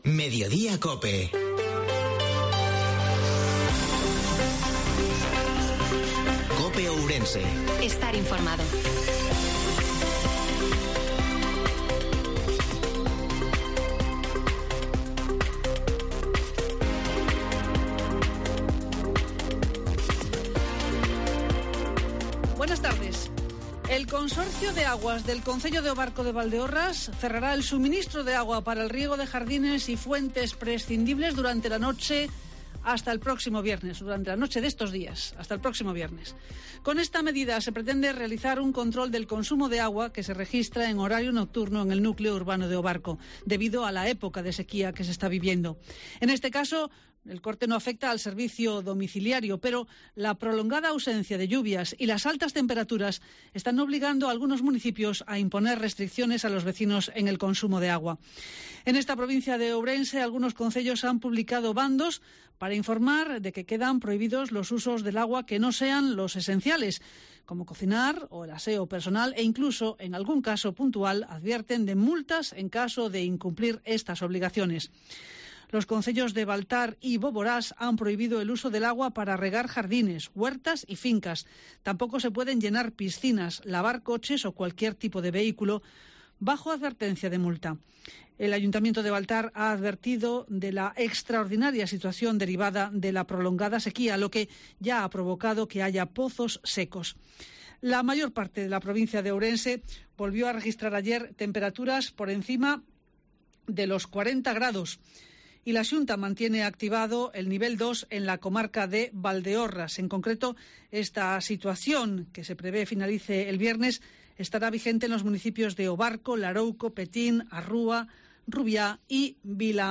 INFORMATIVO MEDIODIA COPE OURENSE-03/08/2022